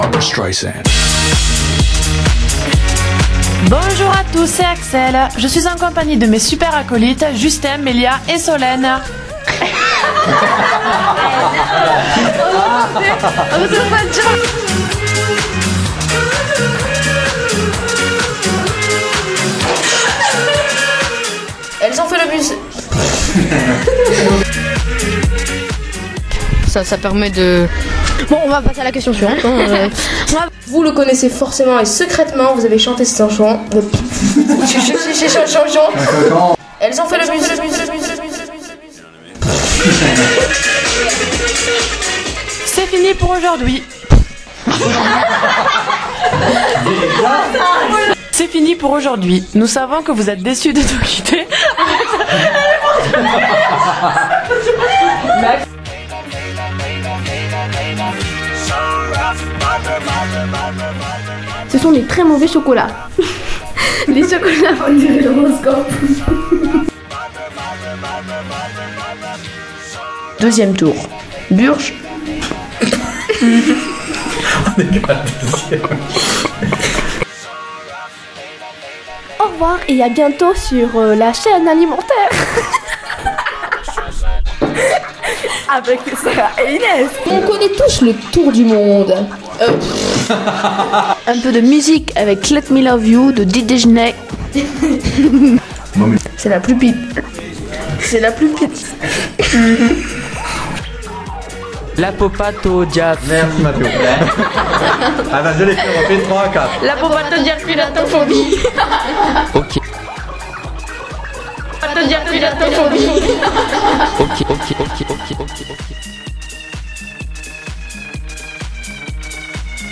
Nos bétisiers en ligne ! Parce que faire de la radio c’est aussi s’amuser !!!